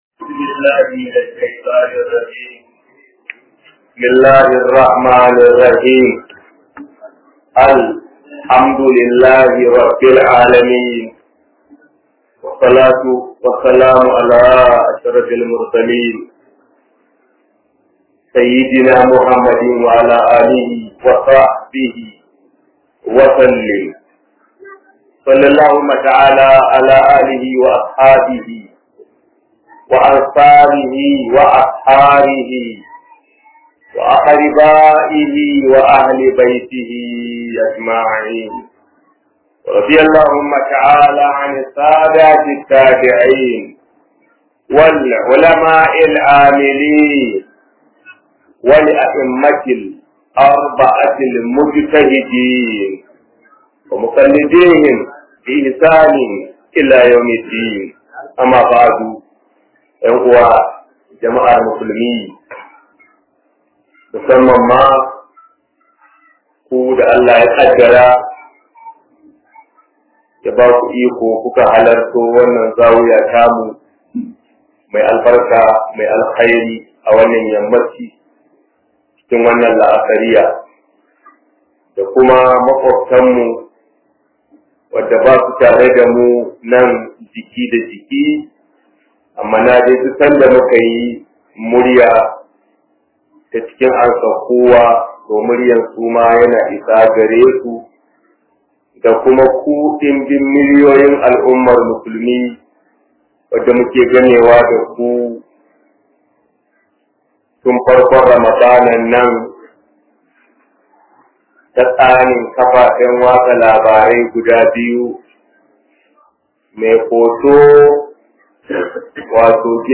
008 Zawiya tafsir 1437..mp3